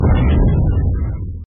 audio_lose.mp3